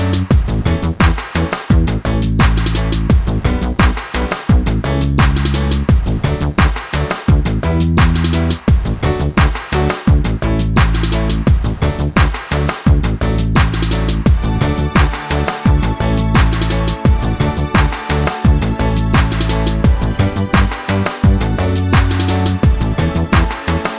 no Backing Vocals Reggae 3:18 Buy £1.50